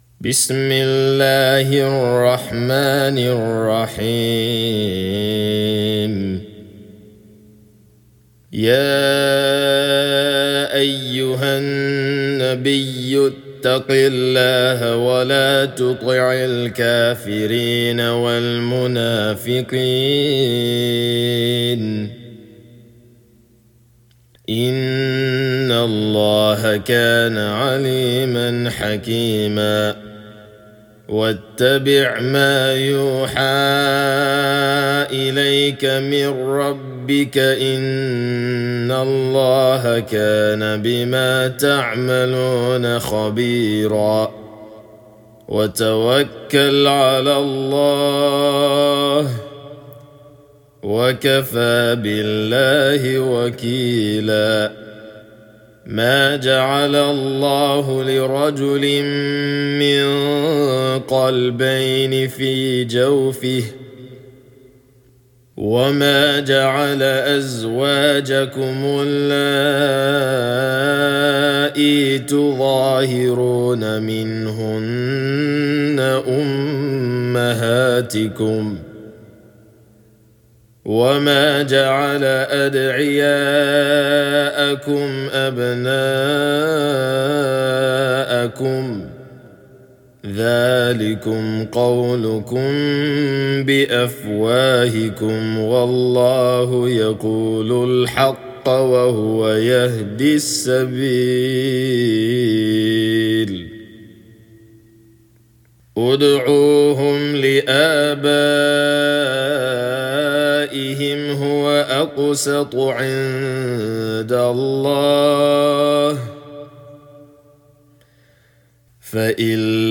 Captions English Al-Ahzab, murattal, Hafs an Asim
Chapter_33,_Al-Ahzab_(Murattal)_-_Recitation_of_the_Holy_Qur'an.mp3